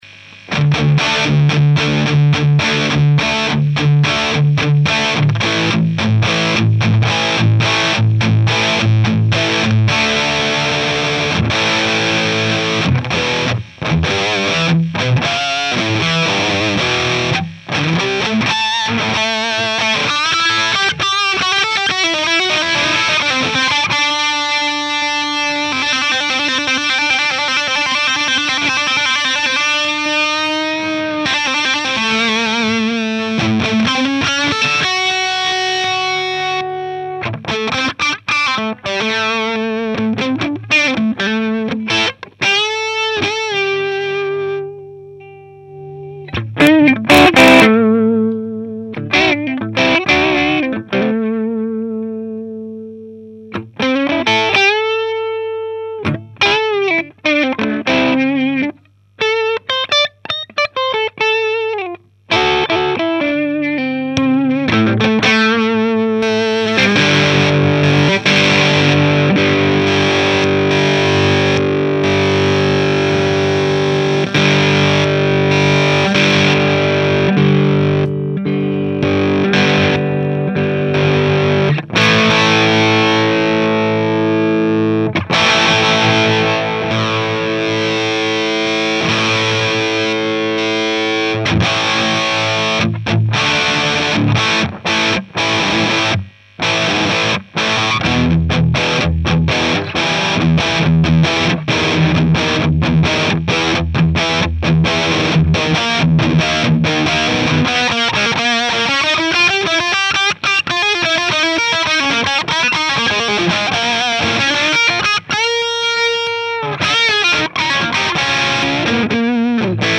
Un autre sample, ou on entend aussi l'effet des switch de boost, et de body, et l'action de l'EQ, juste avant que l'ampli se coupe, toujours a cause du 12V :(
c'est une prise de son avec un Sm-58 devant le HP, un celestion classic lead 80, baffle 1x12 home made, preamp de la table de mix behringer, pas d'effort de placement particulier, parallele a la membrane du Hp, a 10cm environ ...